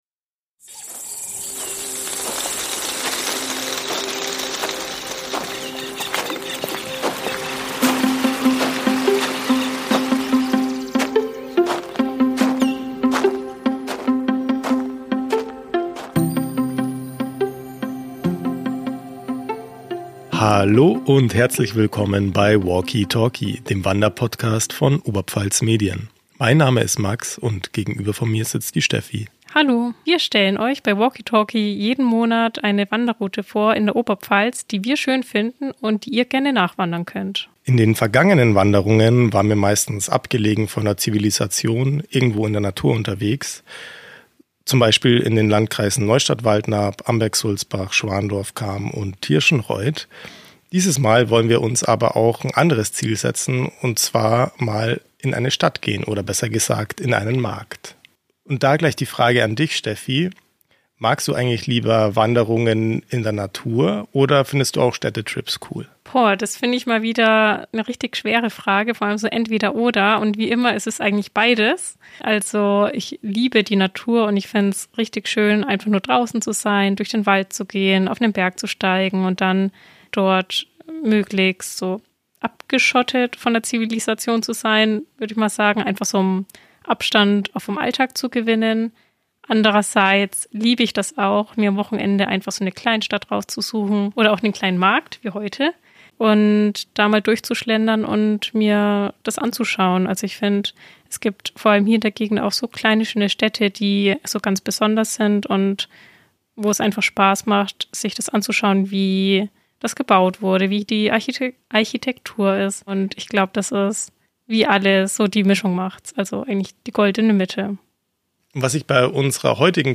Vom Ausgangspunkt bei Traidendorf im Vilstal verläuft die abwechslungsreiche Wanderung durch Wälder und Felder zur Burgruine bei Kallmünz. Auf der Tour besuchen die Reporter das kleinste Wirtshaus der Oberpfalz, entdecken ein Haus im Fels und sprechen über einen Schlangenbiss mit Folgen.